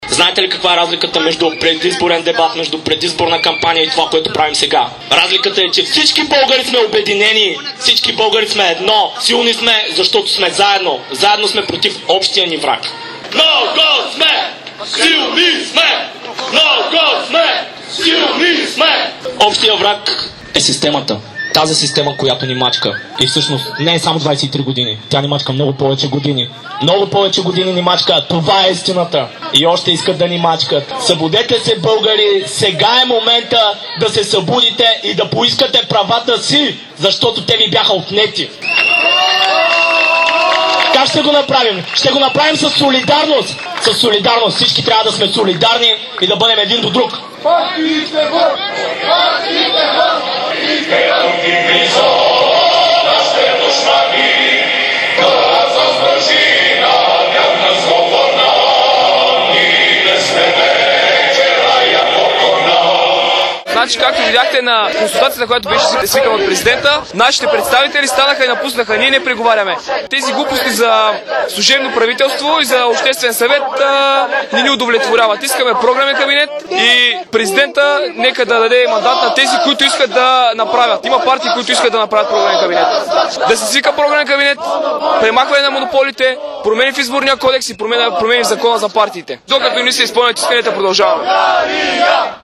Революционни песни и знамена на протеста под Царевец, шествие мина през целия град